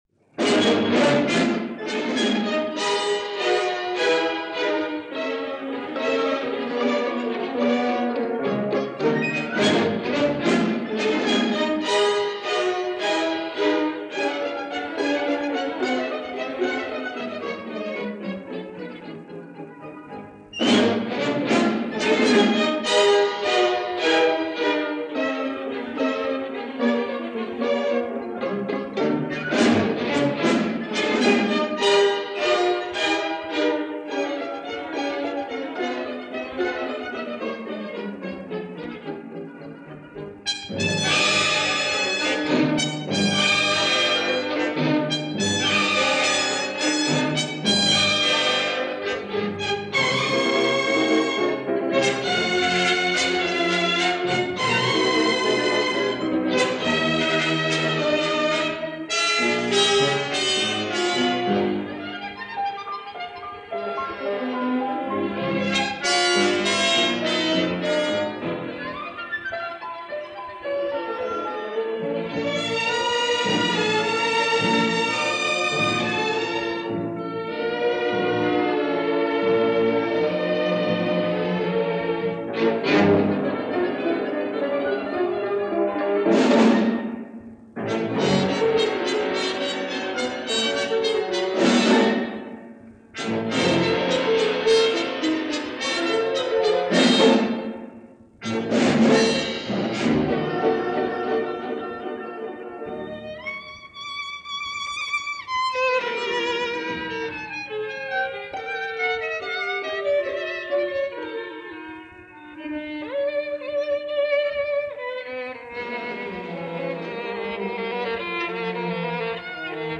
a suite from the ballet
from a radio broadcast